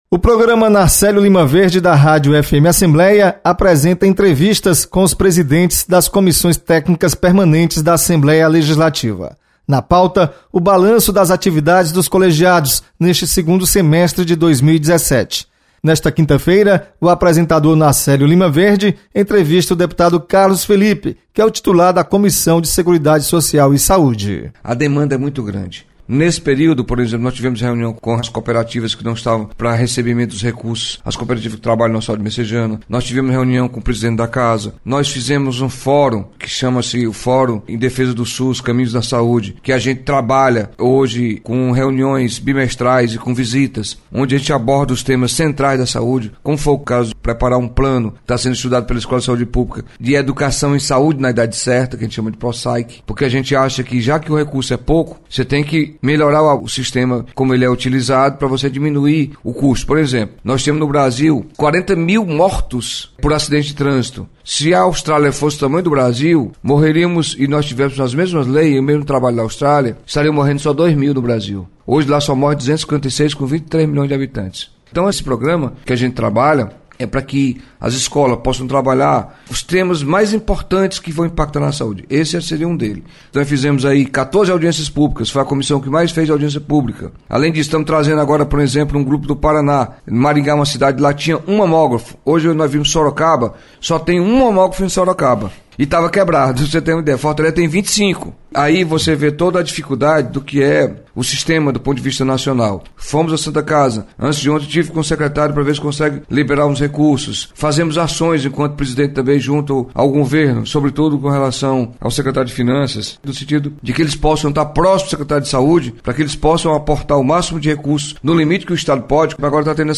Deputado Carlos Felipe faz balanço da Comissão de Seguridade Social e Saúde. Repórter